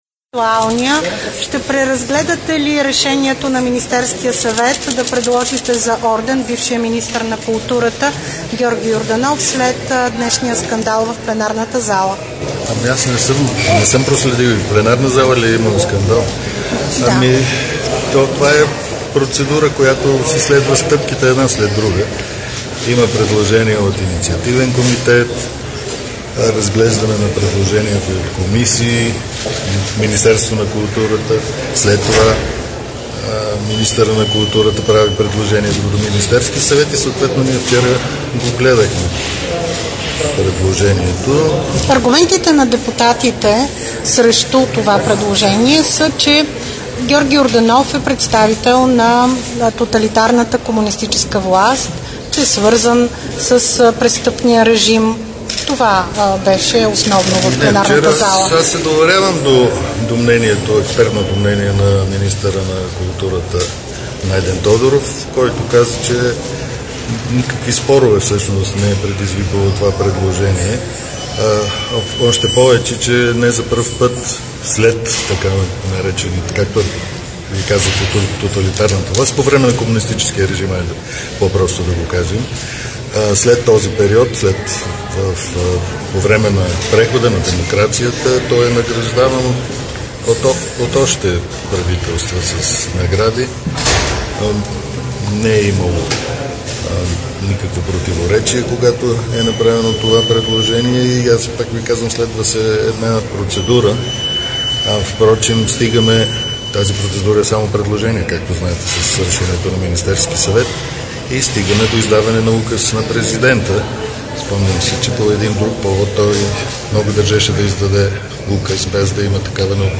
14.45 - ИТН отправя покана за среща към ПГ на БСП за България. - директно от мястото на събитието (зала 142 на Народното събрание)
Директно от мястото на събитието